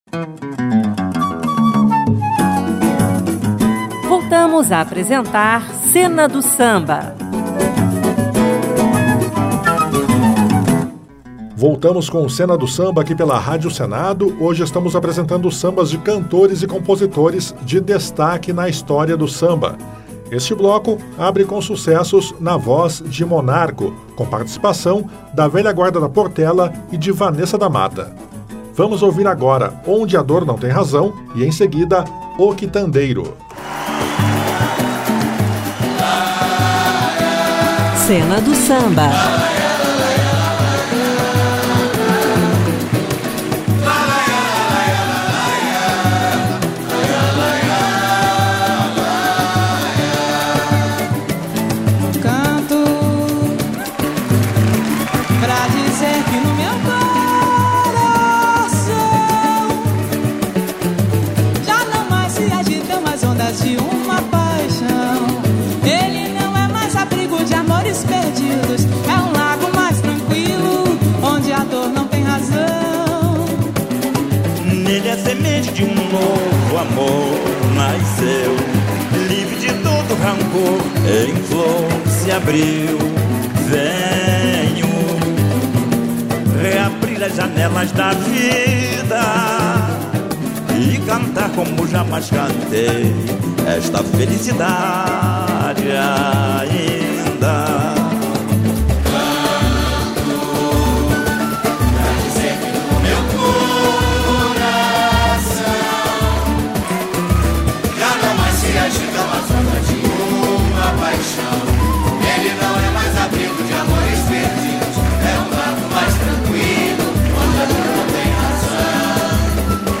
O Cena do Samba apresenta uma playlist especial com sucessos da carreira de vários cantores e compositores que marcaram a história do samba.